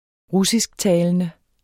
russisktalende adjektiv Bøjning -, - Udtale [ -ˌtæːlənə ] Betydninger 1. som taler russisk grammatik almindelig som substantiv 80 procent af borgerne i byen er primært russisktalende Pol2014 Politiken (avis), 2014.